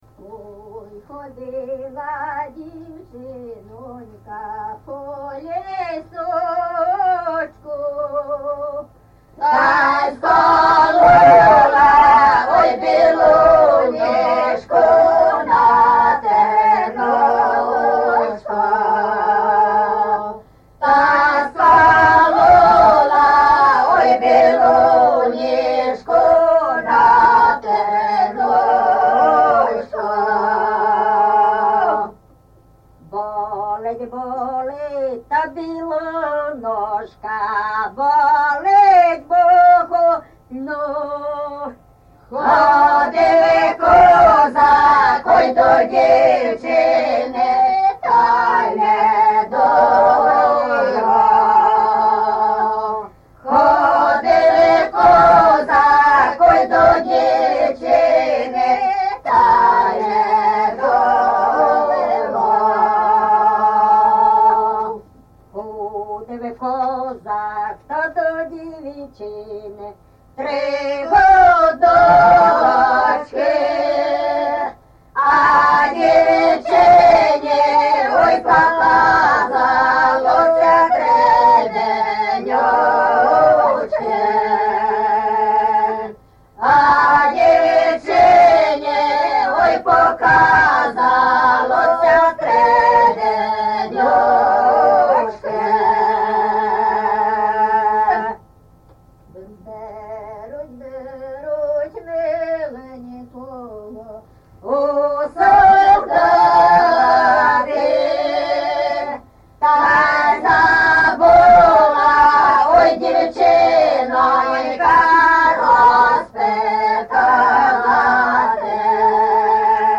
ЖанрПісні з особистого та родинного життя
Місце записус. Лука, Лохвицький (Миргородський) район, Полтавська обл., Україна, Полтавщина